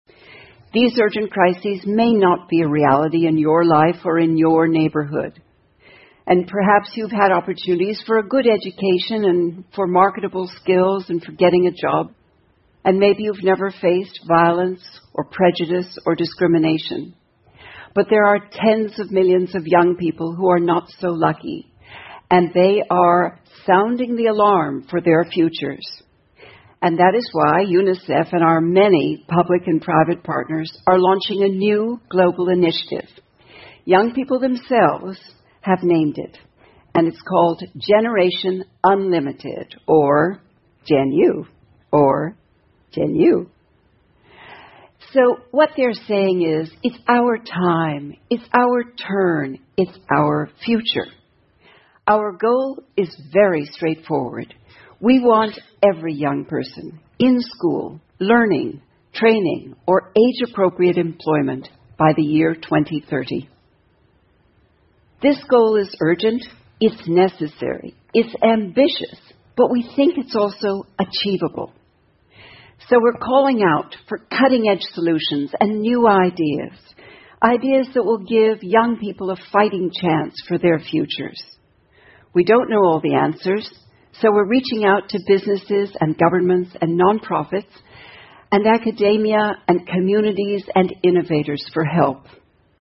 TED演讲:如何帮助年轻人构建更好的未来() 听力文件下载—在线英语听力室